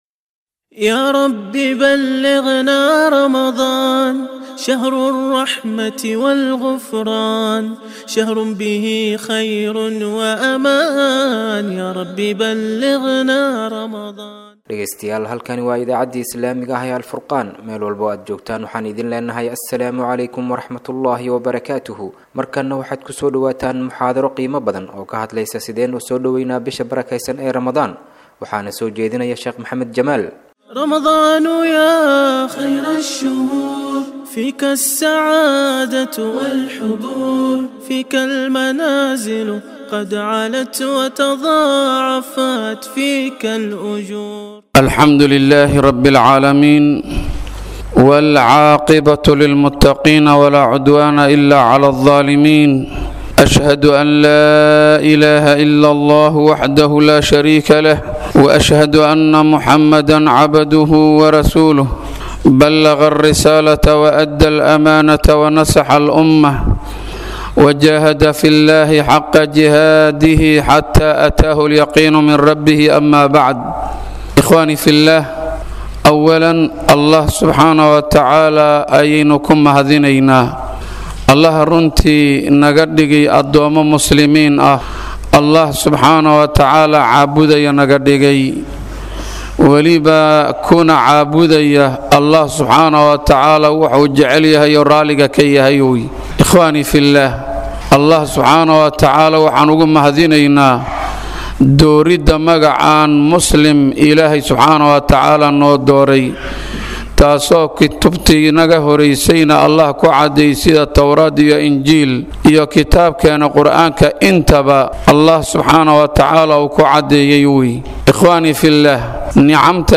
Dhageyso: Muxaadaro ku Saabsan Soo Dhaweynta Bisha Ramadaan